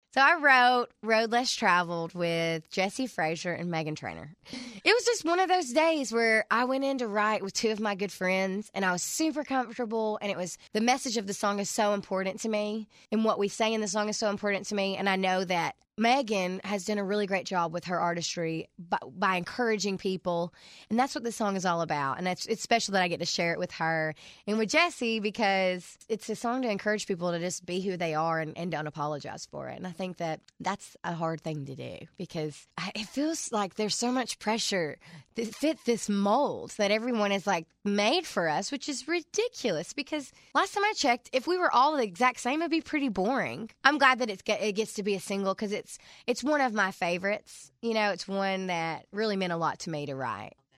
Audio / Lauren Alaina talks about writing her new single, “Road Less Traveled,” with Jesse Frasure and Meghan Trainor.